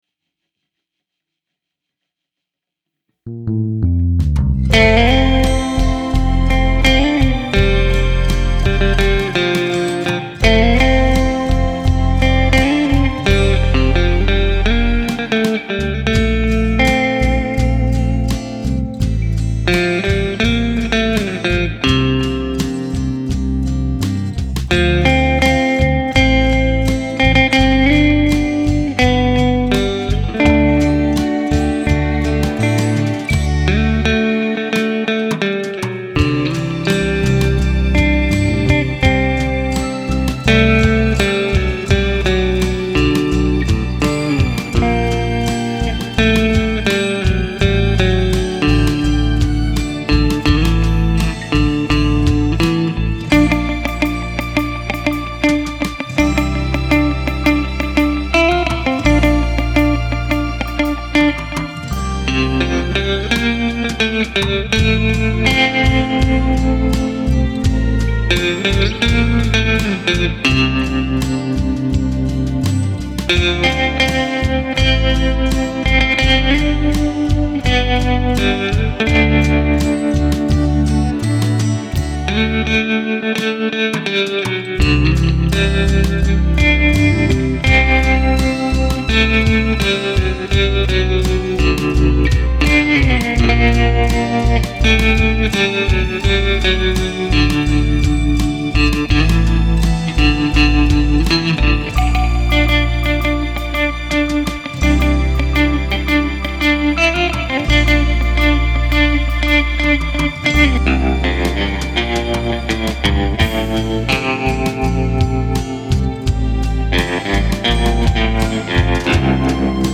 New Jazzmaster
I switched pickups between middle and bridge which are the two positions I use most
That bridge pickup really cuts through